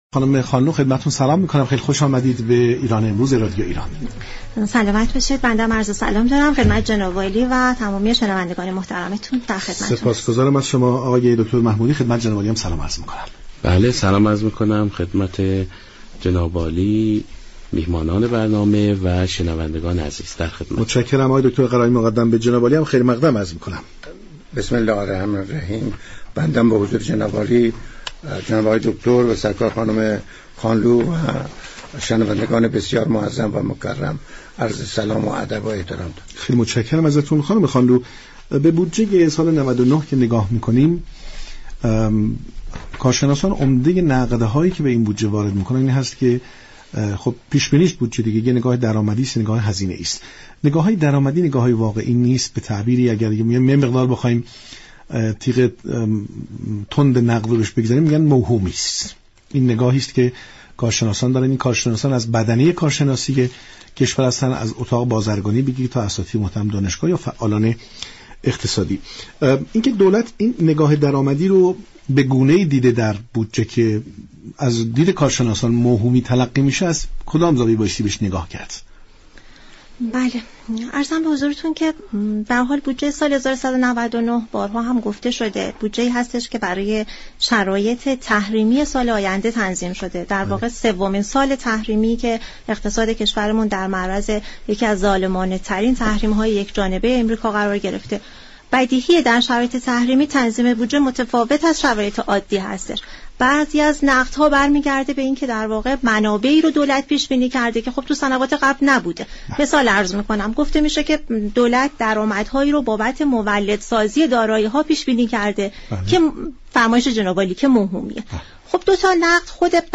گفت و گوی رادیویی